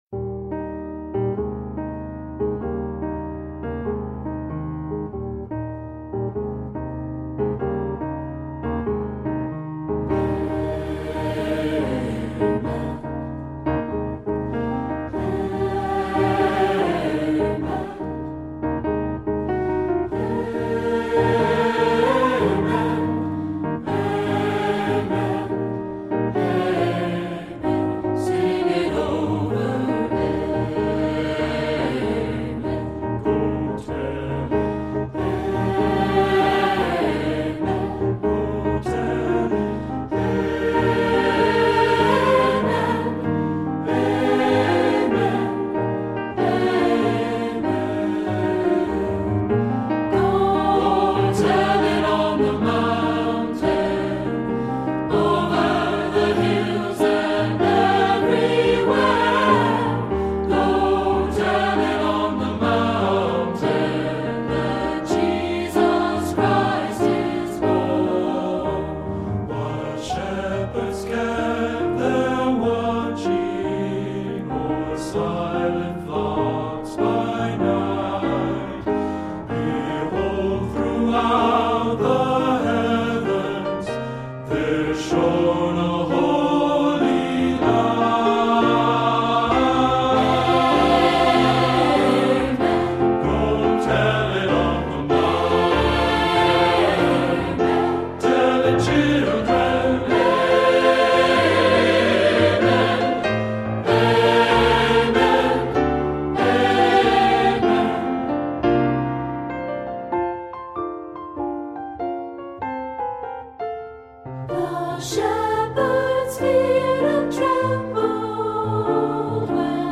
Composer: Spiritual
Voicing: SAB and Piano